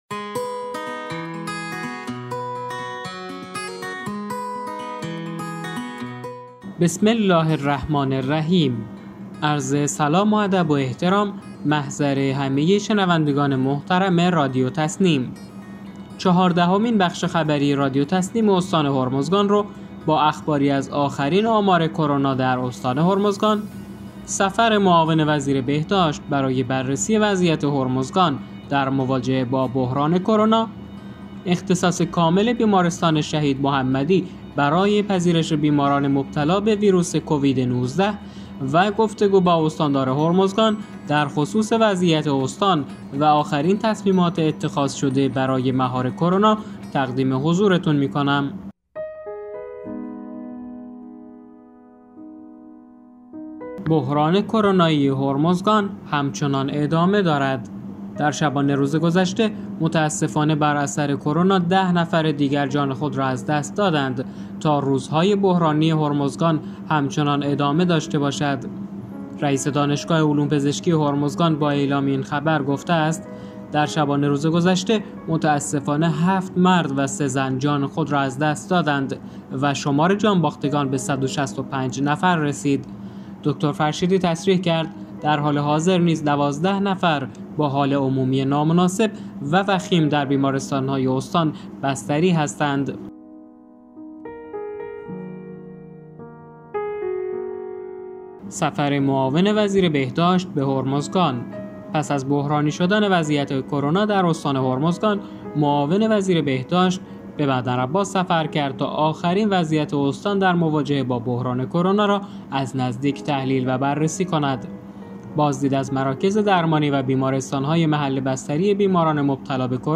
به گزارش خبرگزاری تسنیم از بندرعباس، چهاردهمین بخش خبری رادیو تسنیم استان هرمزگان با اخباری از آخرین آمار کرونا در استان هرمزگان، سفر معاون وزیر بهداشت برای بررسی وضعیت هرمزگان در مواجهه با بحران کرونا، اختصاص کامل بیمارستان شهید محمدی برای پذیرش بیماران مبتلا به ویروس کووید 19 و گفت‌وگو با استاندار هرمزگان در خصوص وضعیت استان و آخرین تصمیمات اتخاذ شده برای مهار کرونا منتشر شد.